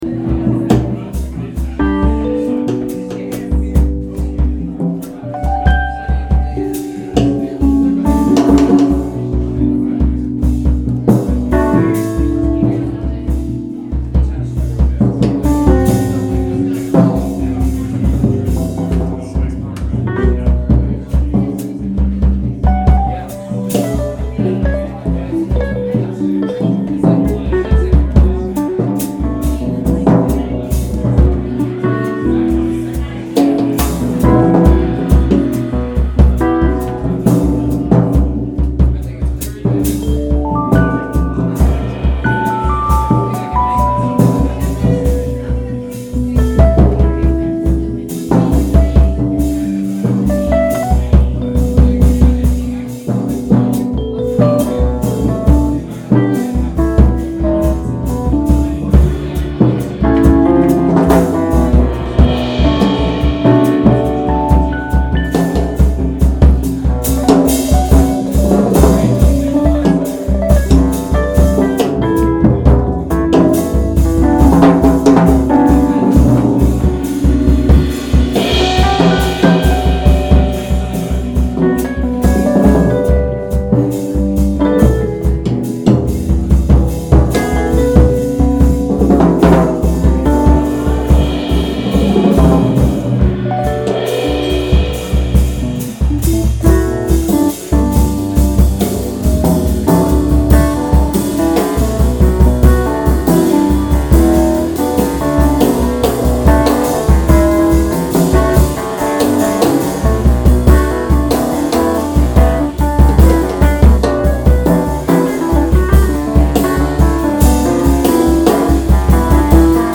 Recorded from live Wave Farm/WGXC webstream.